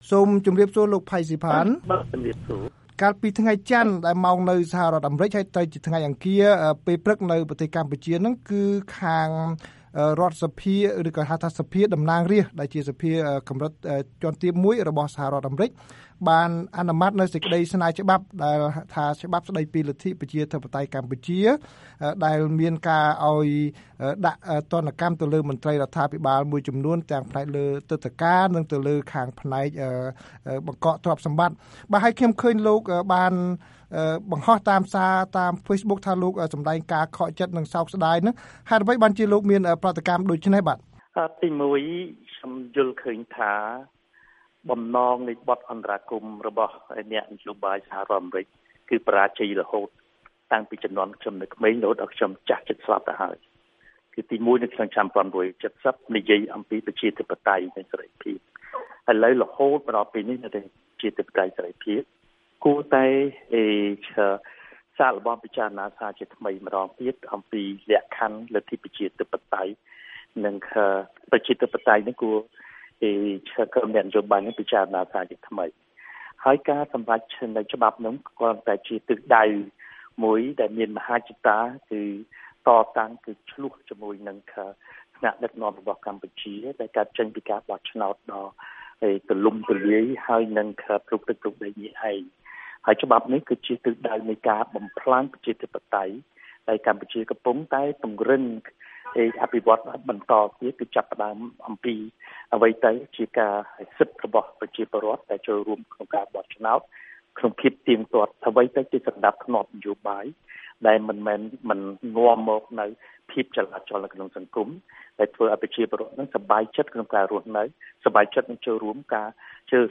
បទសម្ភាសន៍ VOA៖ កម្ពុជាសោកស្តាយក្រោយសភាតំណាងរាស្រ្តអាមេរិកាំងអនុម័តច្បាប់ដាក់ទណ្ឌកម្ម